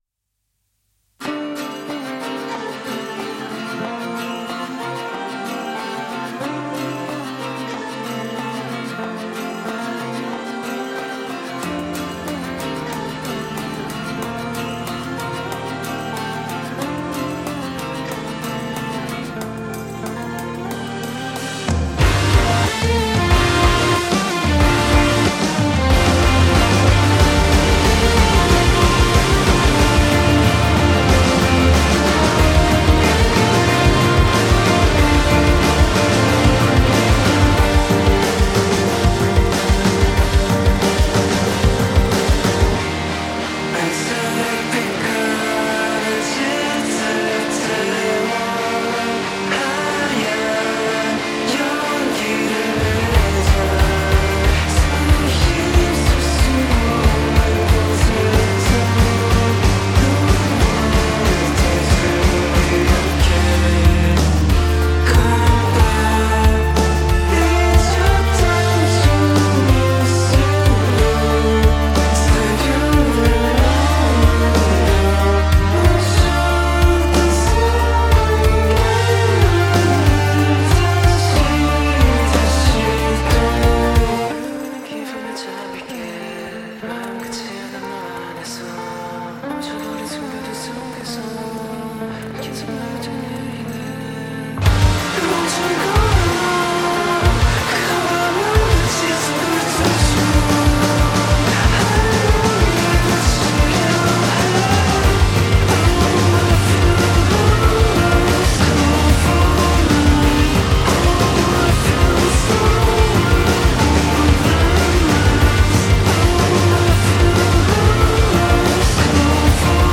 альтернативная этническая  электроника  близкая к прог року